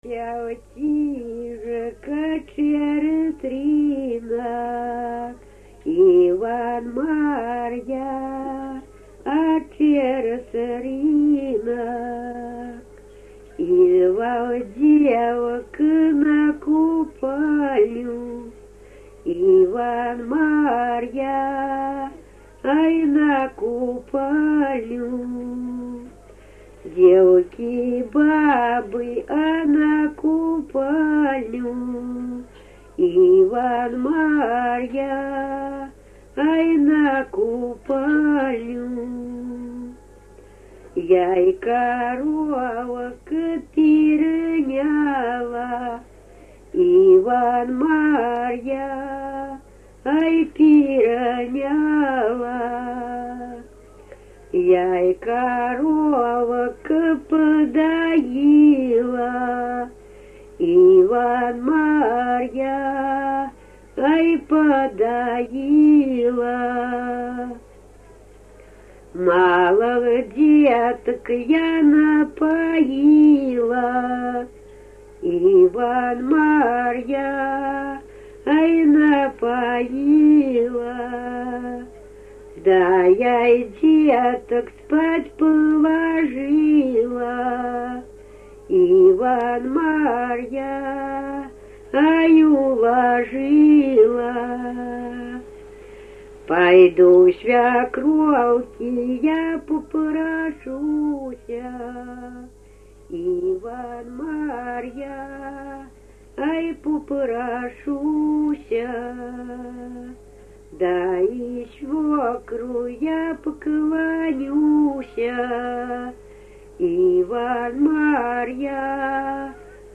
Купальные обрядовые песни Невельского района